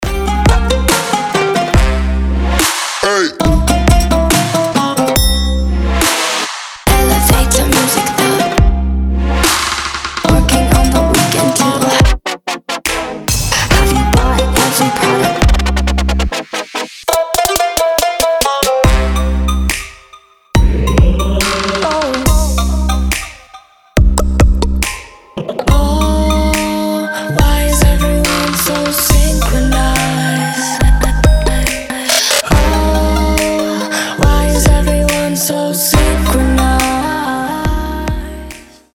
• Качество: 320, Stereo
ритмичные
восточные мотивы
Electronic
EDM
future bass
этнические
Стиль: oriental future bass